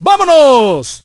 el_primo_start_vo_06.ogg